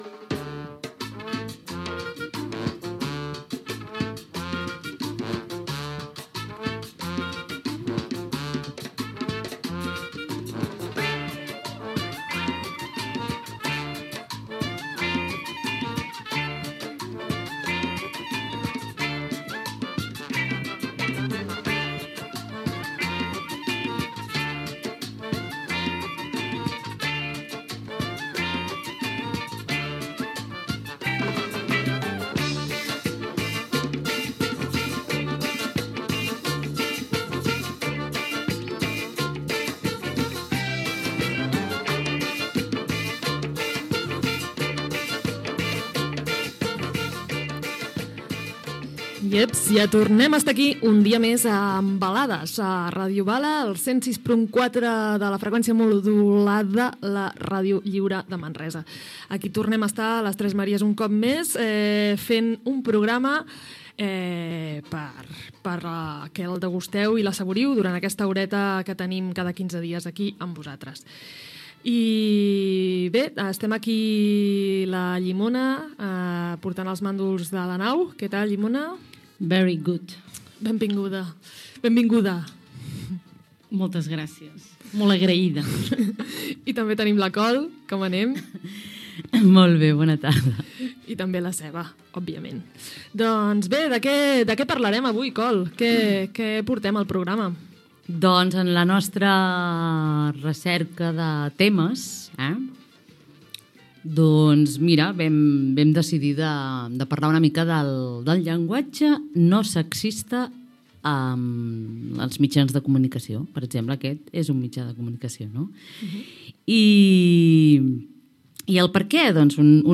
Salutació, amb identificació, equip i presentació del tema del programa: Llenguatge no sexista als mitjans de comunicació.
Informatiu